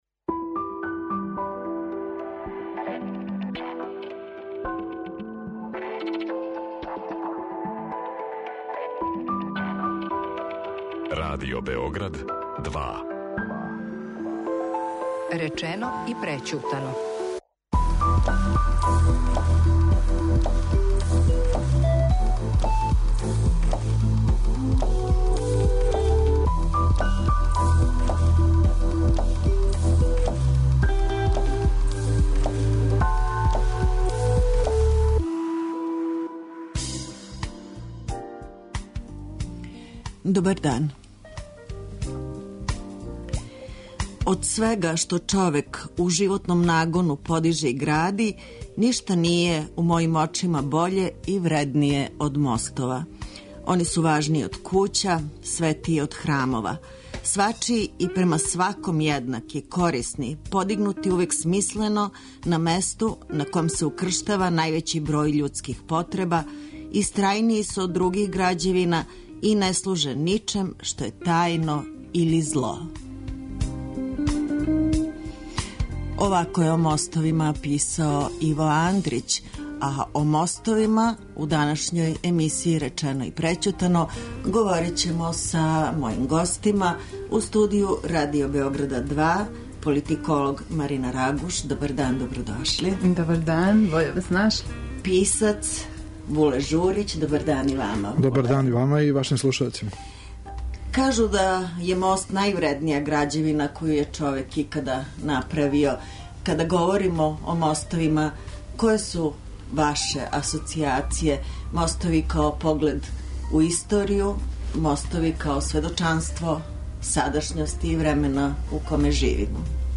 У емисији говоре: писац